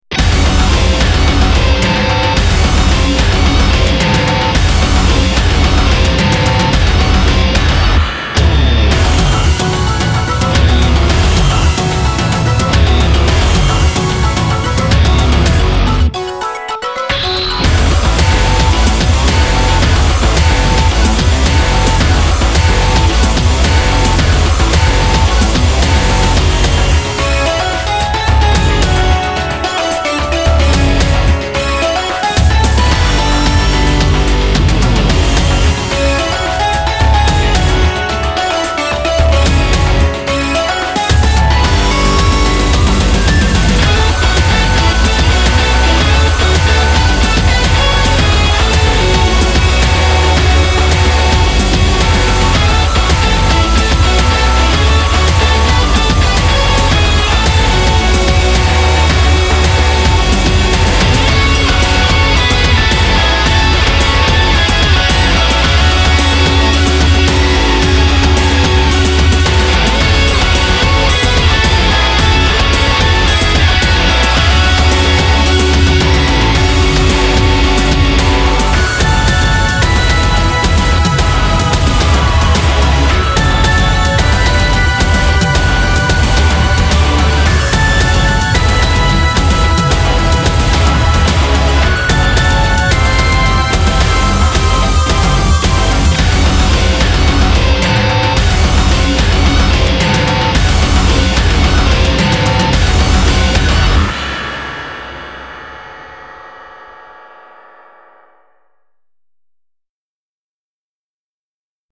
BPM110
Audio QualityPerfect (High Quality)
Genre: DESERT ALTERNATIVE EDGE.